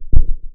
Isoulated/Assets/Audio/Effects/Step2FX.wav at main
Step2FX.wav